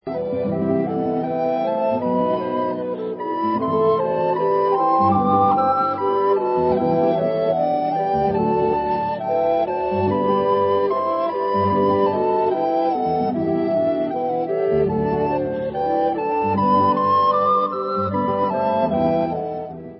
Nejkrásnější vánoční melodie v nových úpravách
houslista
sledovat novinky v oddělení Vánoční hudba